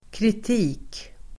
Uttal: [krit'i:k]